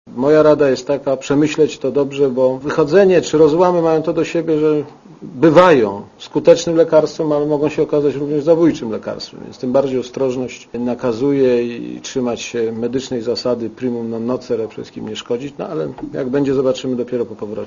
Na konferencji prasowej w Rijadzie powiedział dziennikarzom, że poprosił polityków SLD, by się zastanowili i nie podejmowali pochopnych decyzji i by mógł się z nimi spotkać po swoim powrocie do kraju - prezydent ma wrócić do Polski w środę.
Posłuchaj, co o rozłamie w SLD mówi Aleksander Kwaśniewski